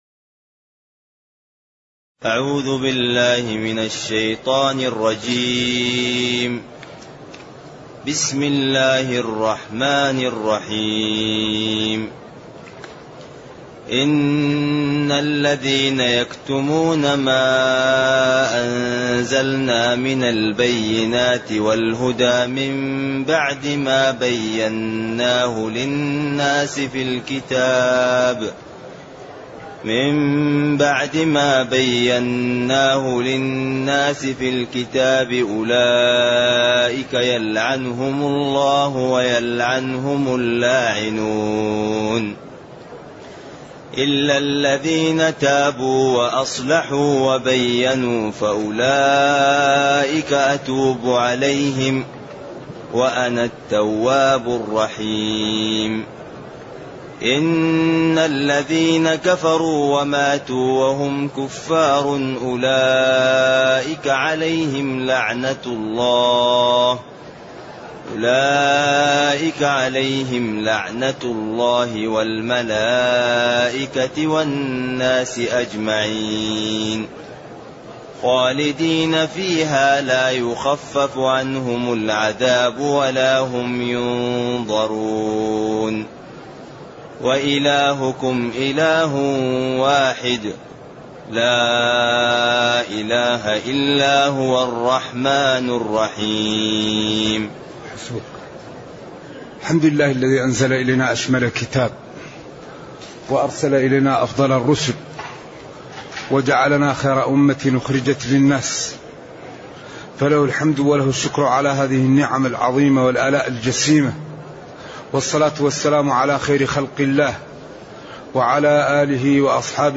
تاريخ النشر ١٤ رجب ١٤٢٨ هـ المكان: المسجد النبوي الشيخ